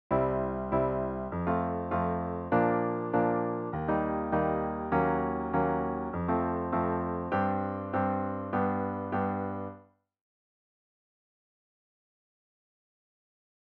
Guitar chords
C  F  Bb  Eb  Ebm7  F  Gsus4  G  C
The progression starts very commonly, in such a way that we either hear the C or the F as a tonic chord.
Then we hear F moving to Gsus4 and then G, and we feel confident that we’re now in C major.